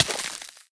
Minecraft Version Minecraft Version 1.21.5 Latest Release | Latest Snapshot 1.21.5 / assets / minecraft / sounds / entity / player / hurt / freeze_hurt4.ogg Compare With Compare With Latest Release | Latest Snapshot
freeze_hurt4.ogg